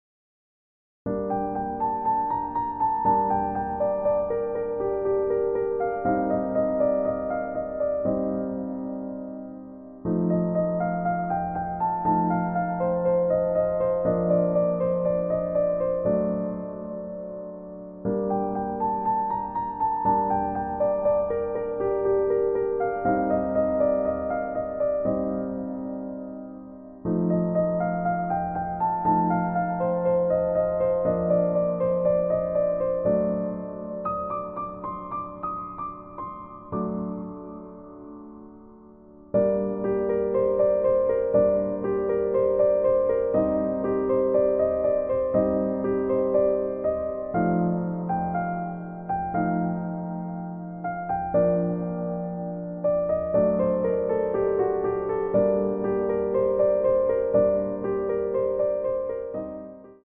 EASY MEDIUM Piano Tutorial